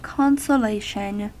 Ääntäminen
IPA : [ˌkɒn.sə.ˈleɪ.ʃən]